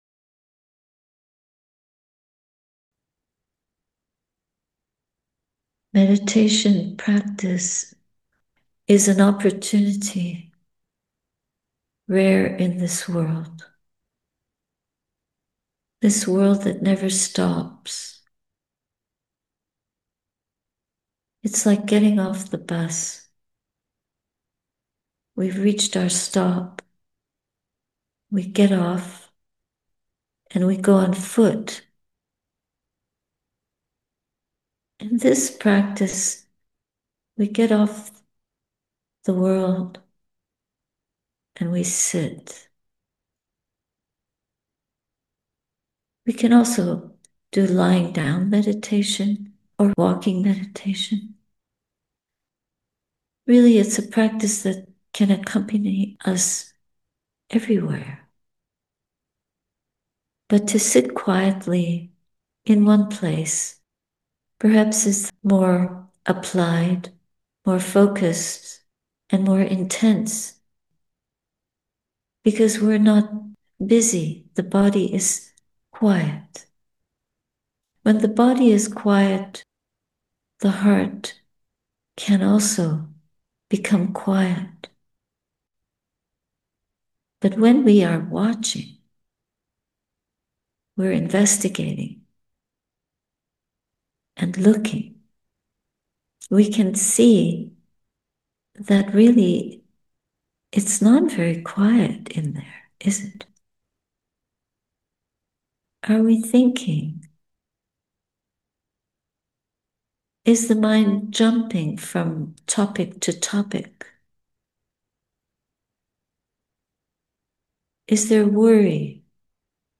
Enter the Timeless – Guided Meditation
We enter the timeless, tenderly knowing the joy and unsurpassable refuge of this universal silence – not out there, but right here within us. Meditation reflections at the Ottawa Buddhist Society, Oct. 17, 2025